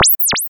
贡献 ） 分类:游戏音效 您不可以覆盖此文件。
se_warpr.mp3